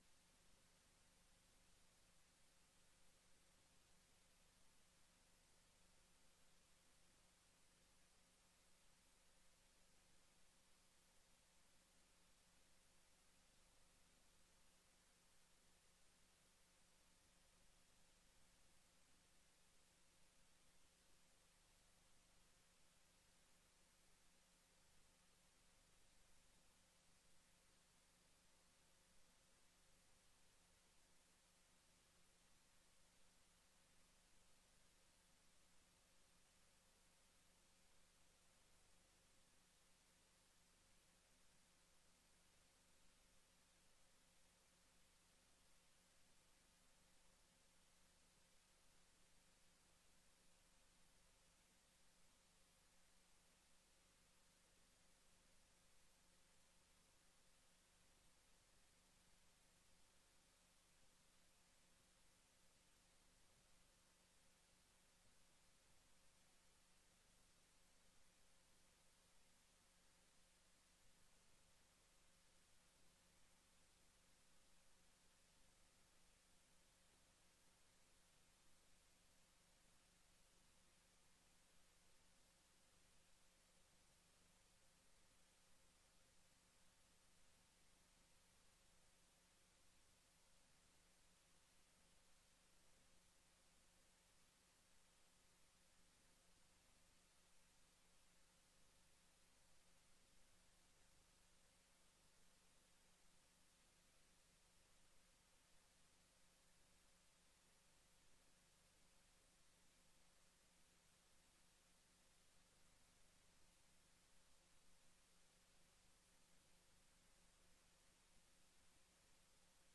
Besloten raadsvergadering 28 oktober 2025, aanbeveling voor benoeming nieuwe burgemeester